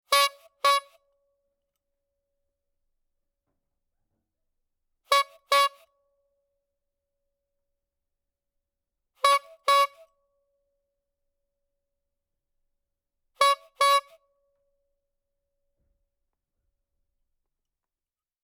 clown nose